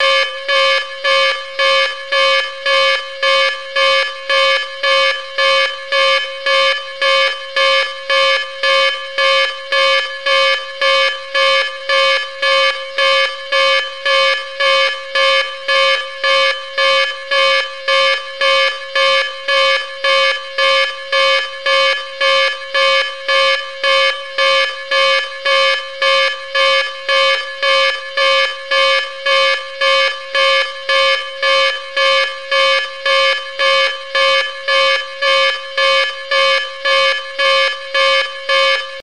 館内に響き渡る警告音です。アラーム警告音02の高音バージョン。高音のいやな感じがアップしました。